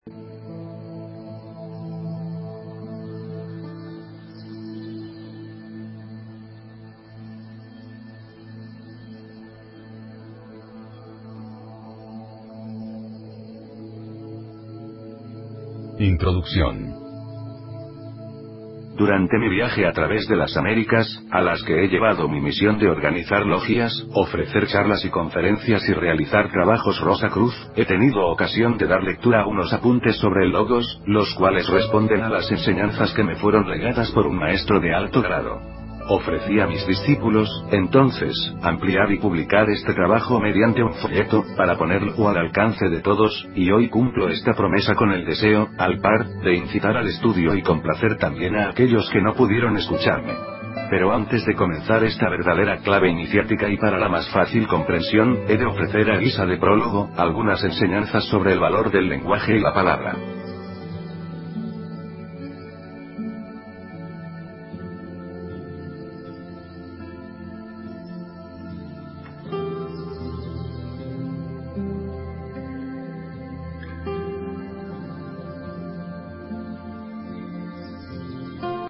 Audio Libro